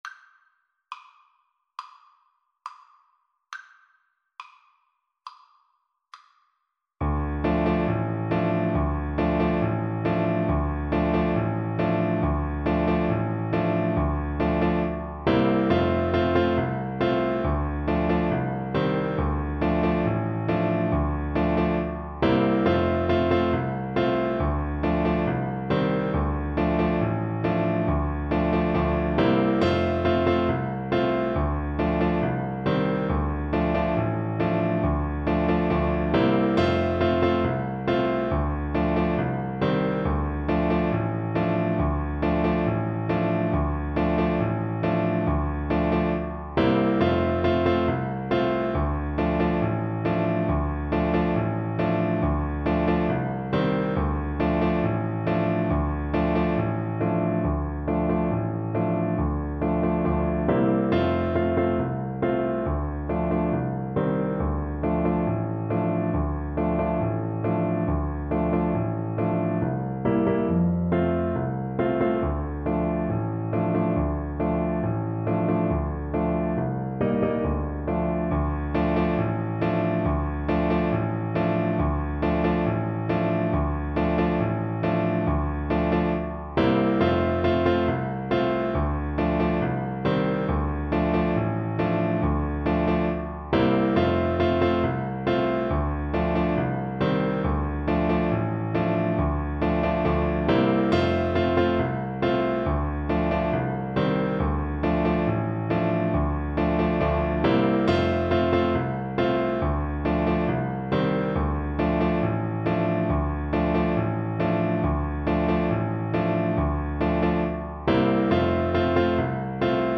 World Trad. Ikariotikos (Greek Folk Song) Alto Saxophone version
Moderato